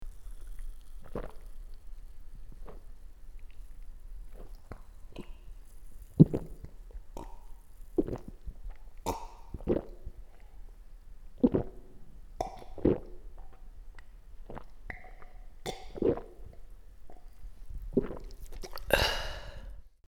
Звук глотка Кока-Колы, приятный звук питья